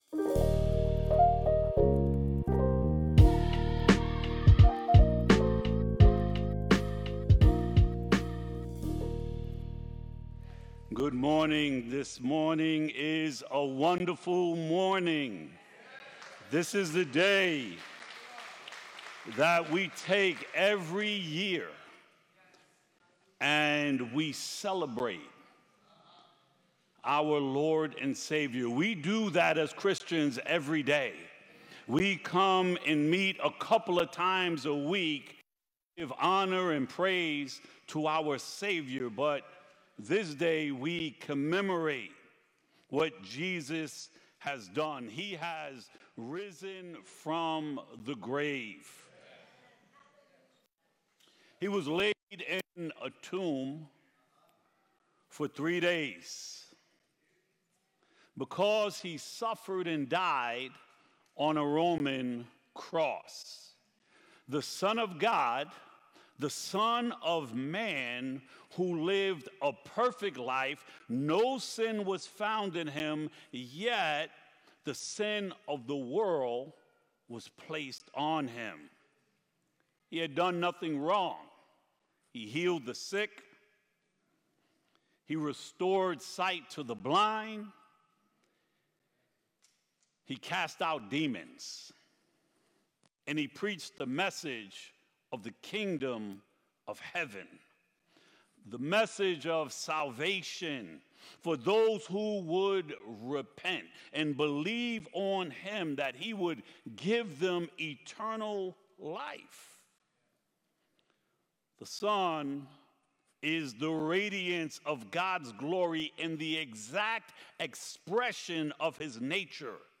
Sermons from Light of the World Church in Minisink Hills, PA